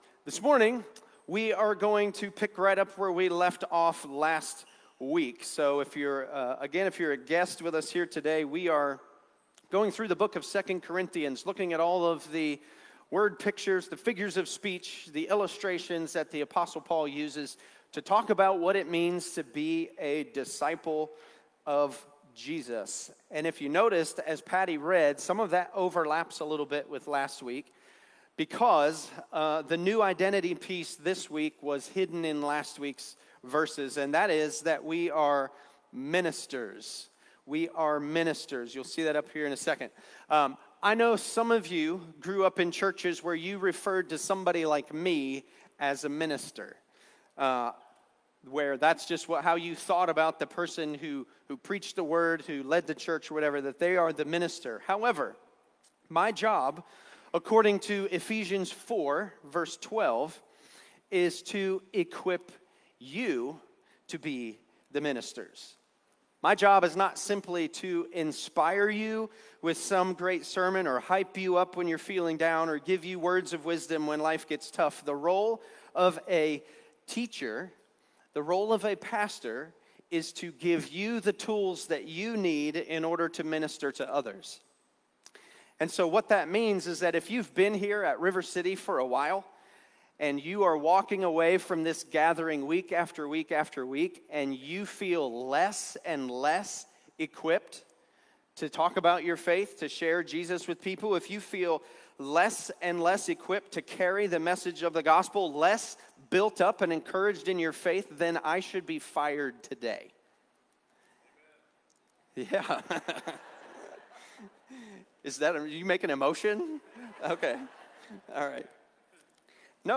River City’s weekly gathering on Sunday mornings